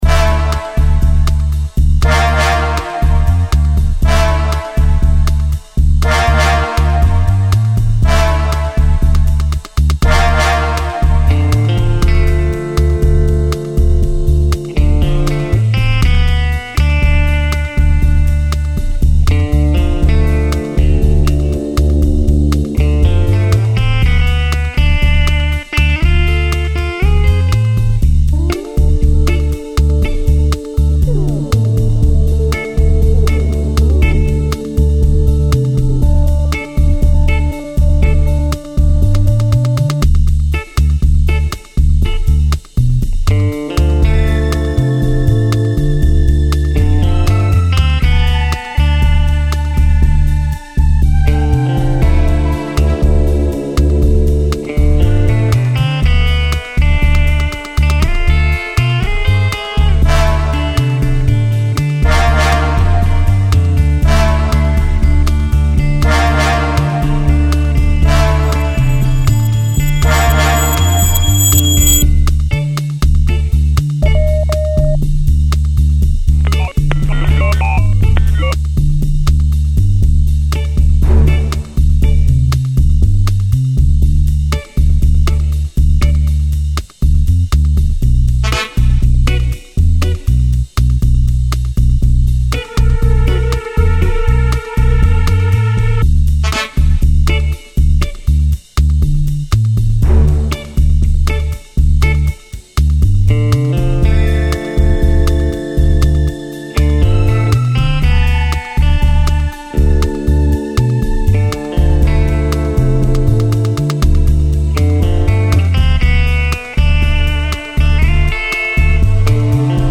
Bossabond ( Musique tirée du ciné-concert "le mécano de la Générale")
compositeur et programmateur de musique electronique / technicien son /régisseur plateau /machiniste